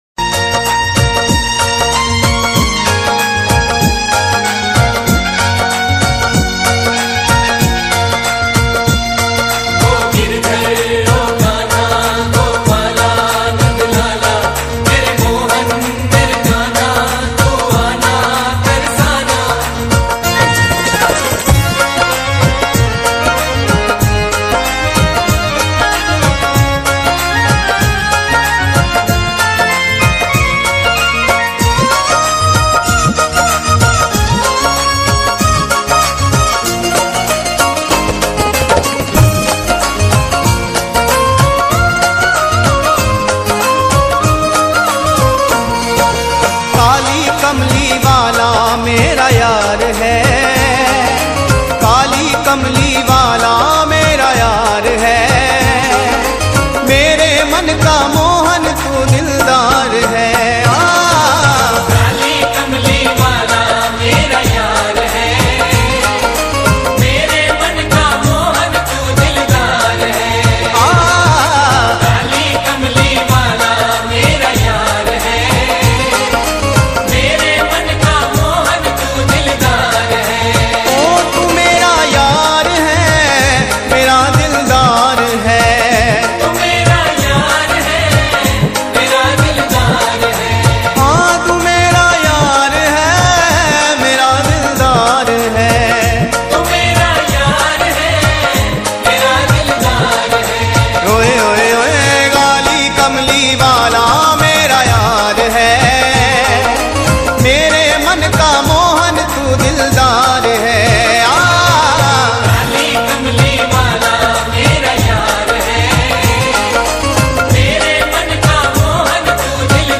यह श्री कृष्ण जी का बहुत ही लोकप्रिय भजन है